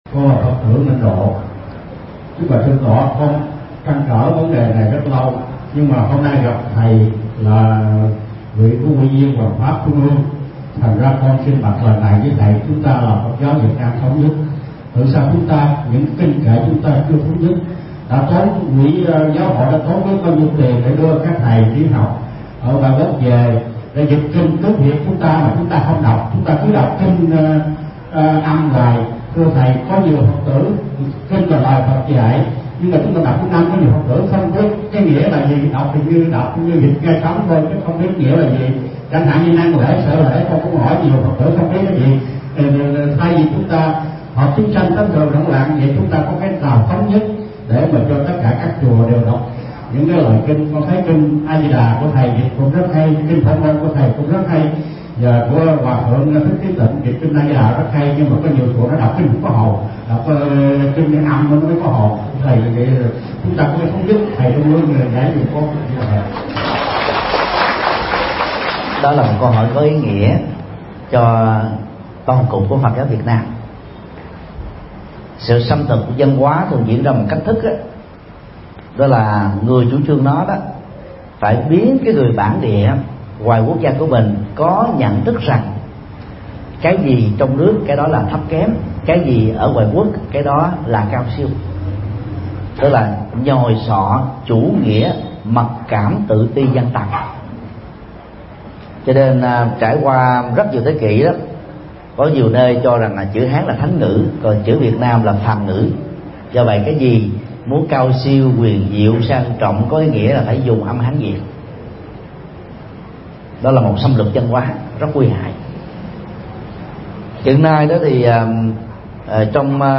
Vấn đáp: Đạo Phật nguyên chất ( nguyên thuỷ) – Thầy Thích Nhật Từ